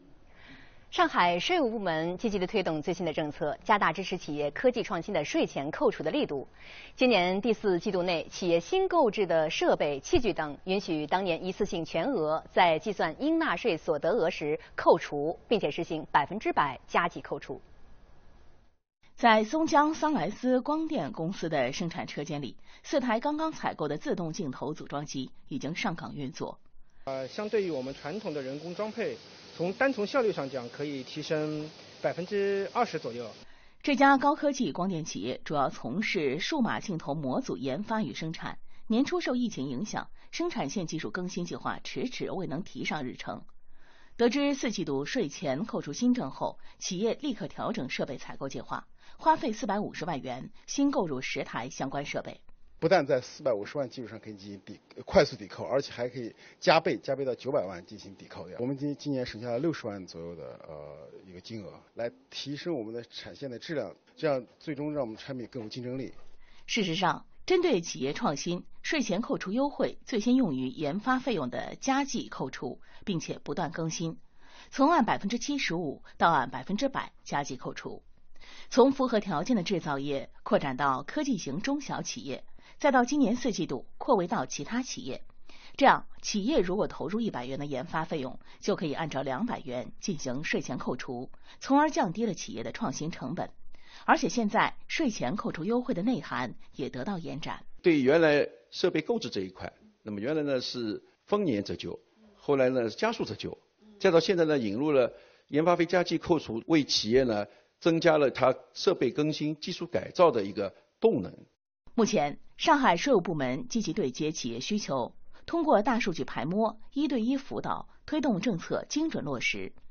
视频来源：东方卫视《东方新闻》